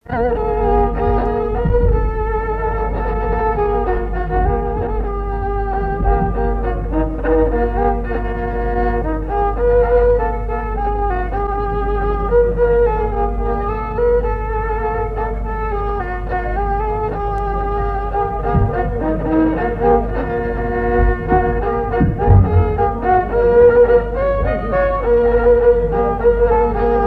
Lettrées patoisantes
Assises du Folklore
Pièce musicale inédite